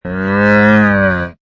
Camel 7 Sound Effect Free Download